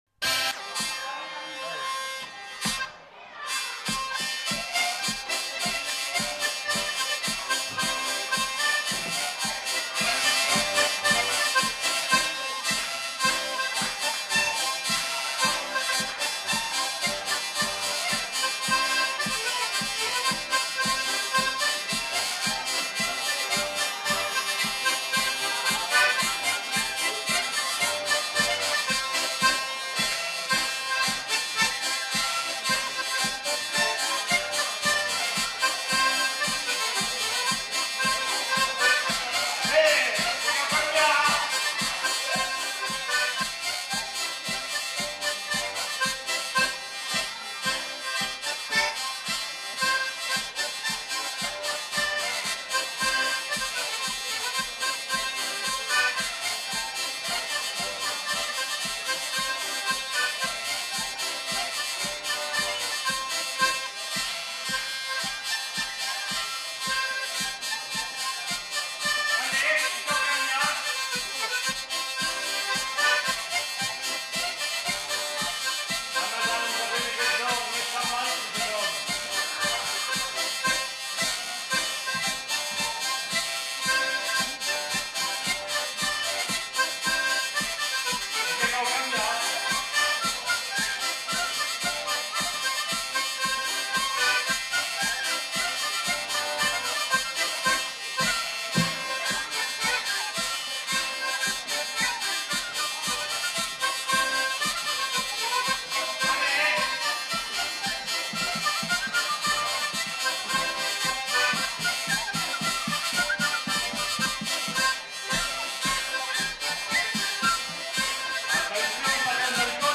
Aire culturelle : Gabardan
Lieu : Houeillès
Genre : morceau instrumental
Instrument de musique : vielle à roue ; accordéon diatonique ; flûte à bec ; violon
Danse : polka
Notes consultables : Le joueur de flûte n'est pas identifié.